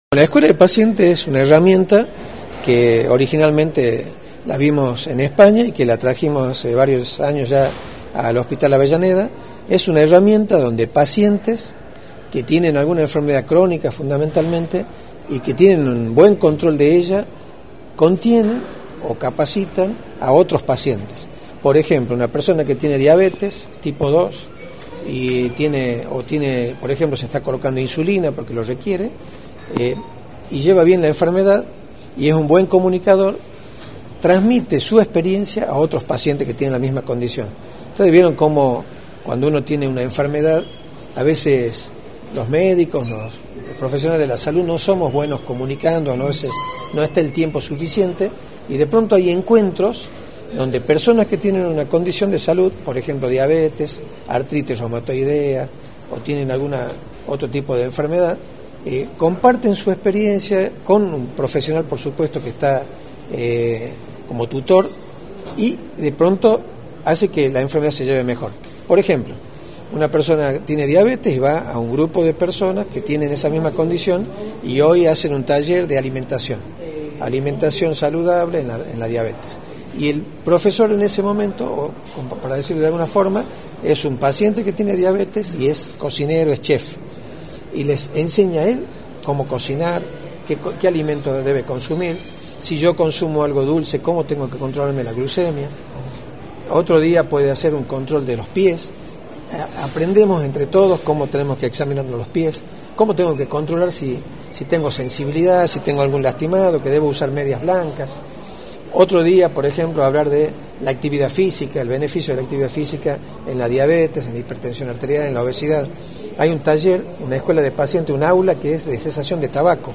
El Dr. Luís Medina Ruiz, Ministro de Salud, informó en Radio del Plata Tucumán, por la 93.9, los alcance de la Escuela de Pacientes, la cual se encarga de fomentar la contención entre pacientes que padecen la misma condición.